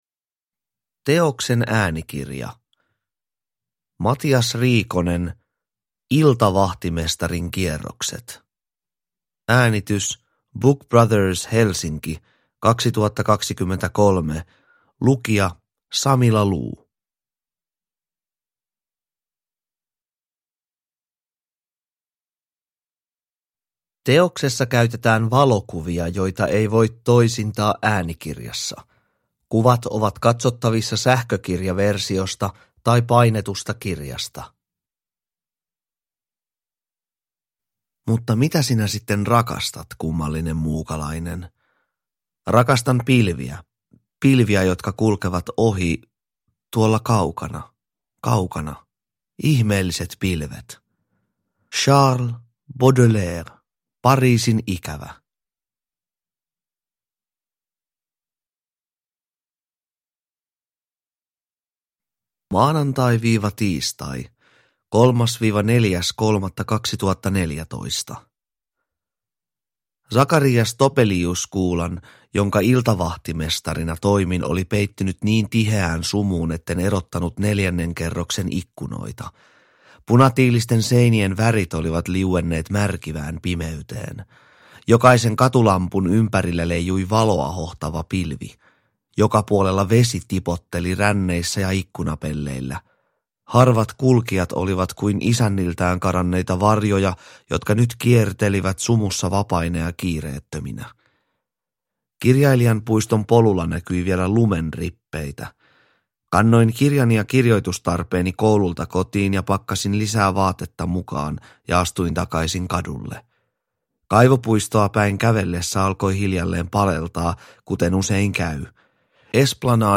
Iltavahtimestarin kierrokset (ljudbok) av Matias Riikonen